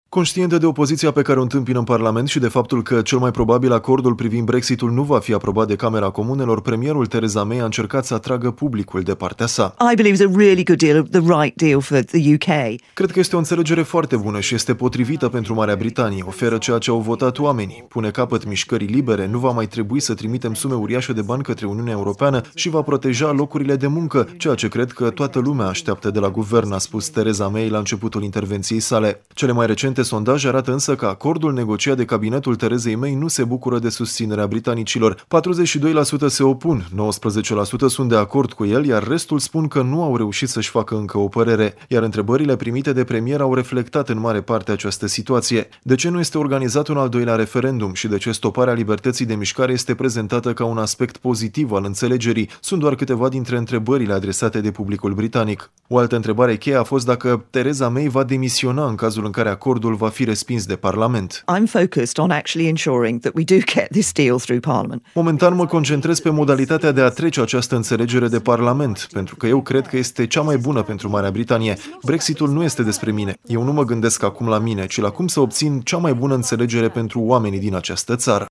relatează de la Londra: